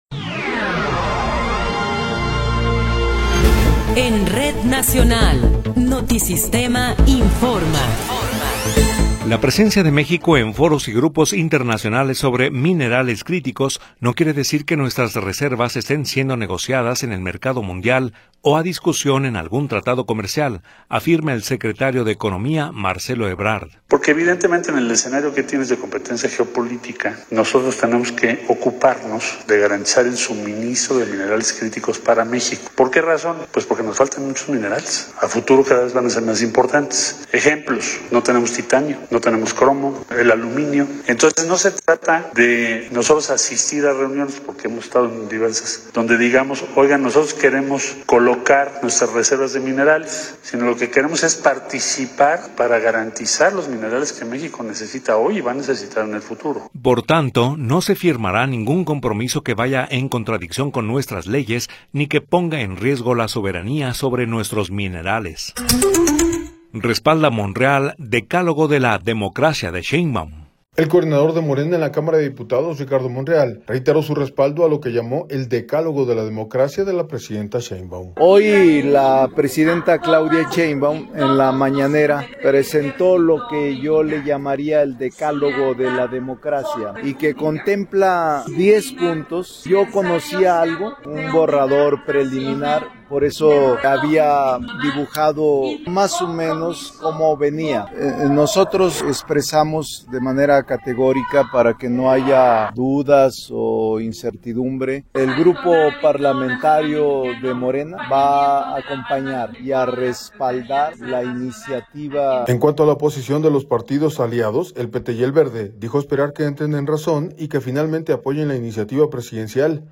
Noticiero 18 hrs. – 28 de Febrero de 2026